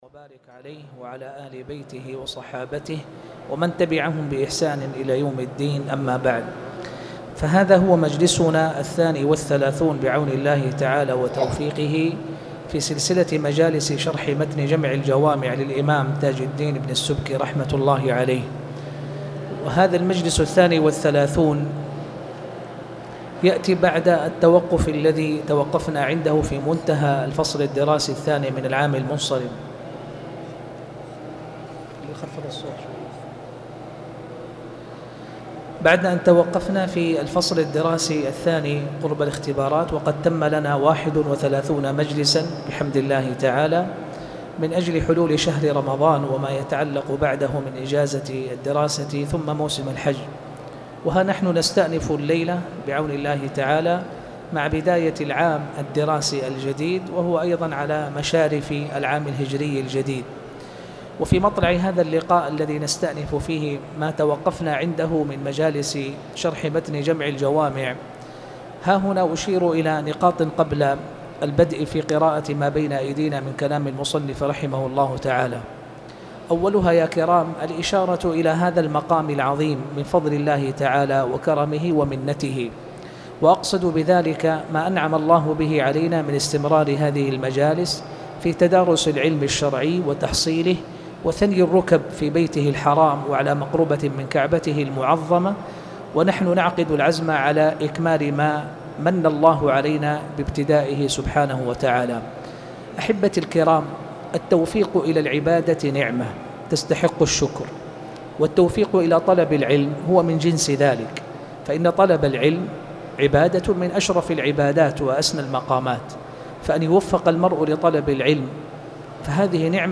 تاريخ النشر ٢٦ ذو الحجة ١٤٣٧ هـ المكان: المسجد الحرام الشيخ